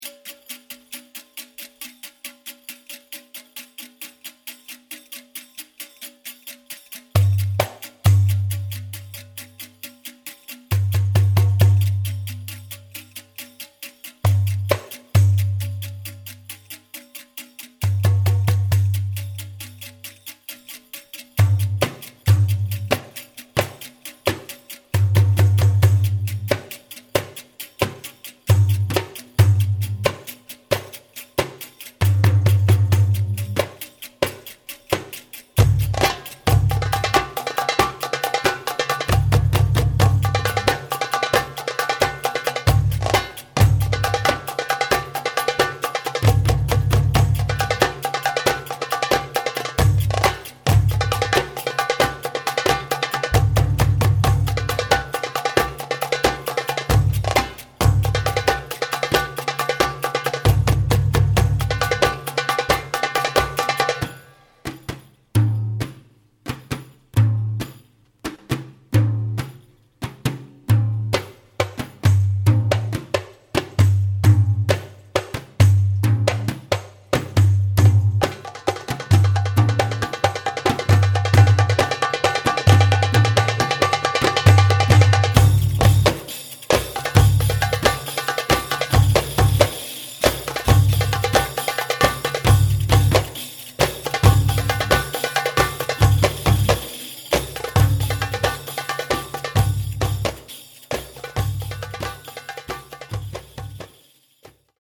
middle-eastern drums: